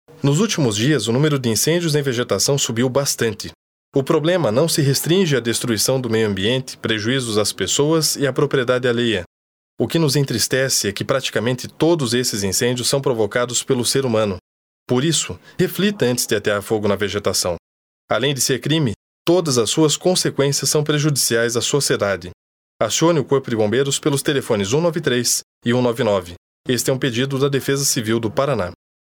Várias rádios receberam o spot e estão difundindo as informações da campanha durante sua programação.